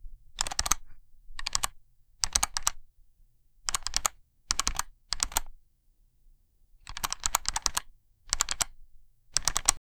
Keyboard key press, gentle, clicky
keyboard-key-press-gentle-of6fefpc.wav